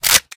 spring.ogg